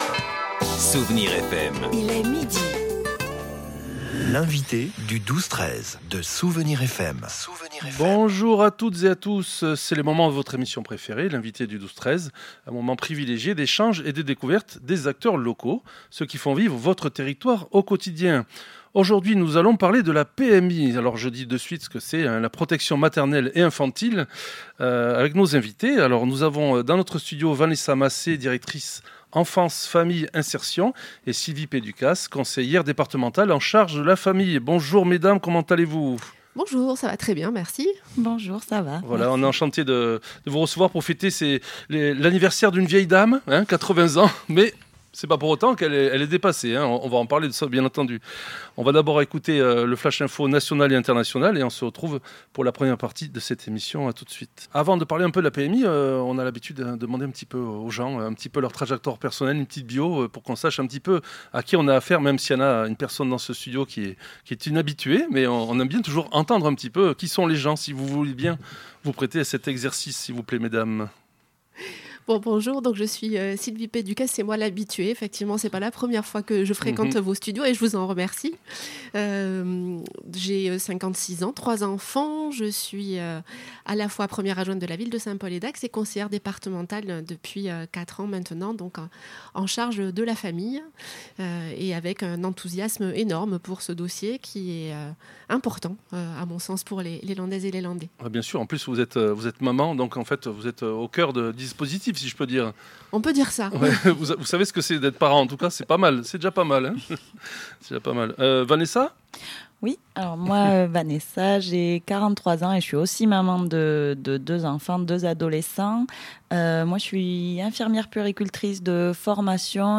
Nos invités sont venus nous parler de la PMI (Protection Maternelle et Infantile) qui fête ses 80 ans !